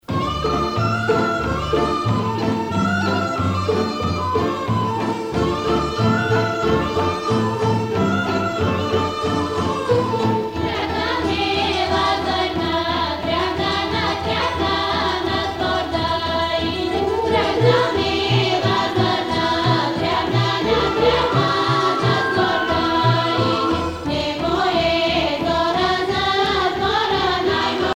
Pièce musicale orchestrée n°16 avec choeur
Bulgarie
Pièce musicale inédite